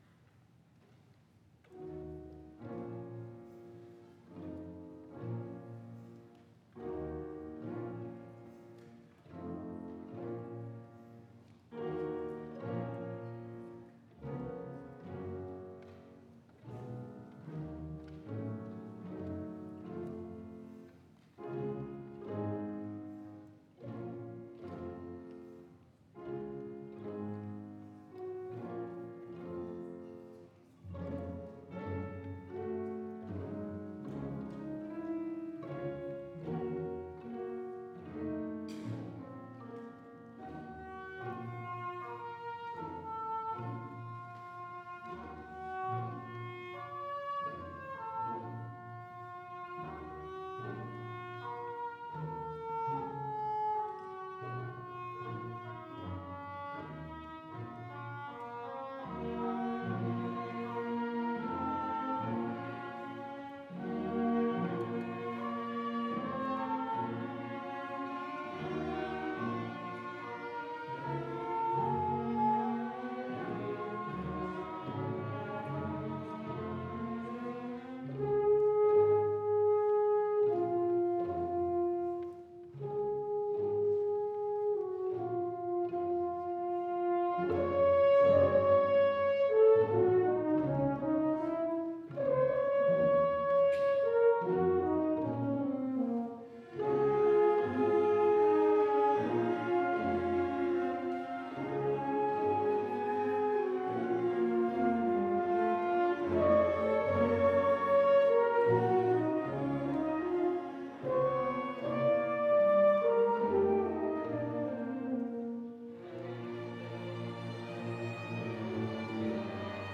Spring Concert